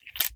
38 SPL Revolver - Loading 001.wav